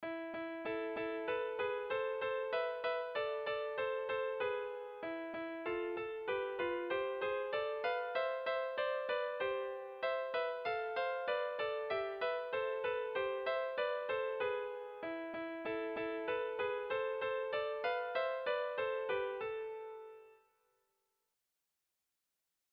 Erlijiozkoa
Hiru abotsetara kantatzeko eran moldatua dator doinu eder hau.
Zortziko ertaina (hg) / Lau puntuko ertaina (ip)
A1A2BA2